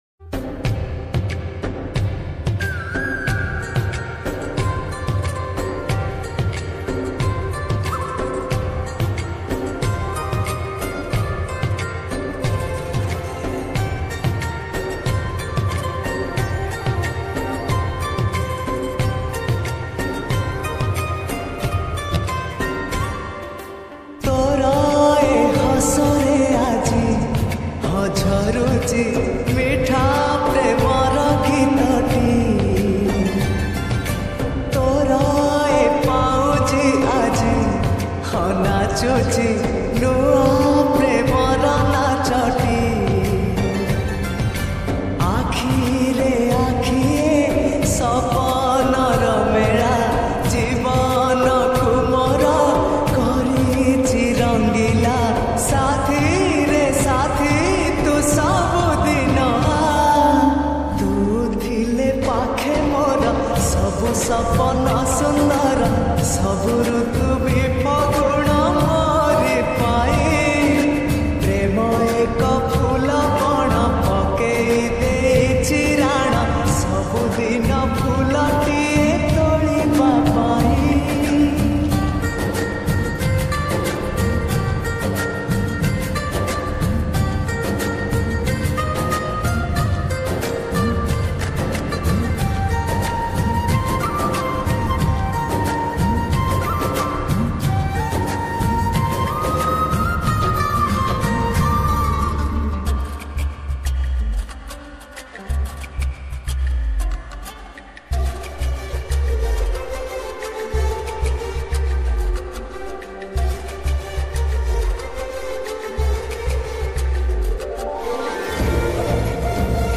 Category : odia lofi song